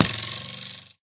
bowhit4.ogg